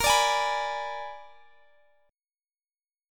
Bb7b9 Chord
Listen to Bb7b9 strummed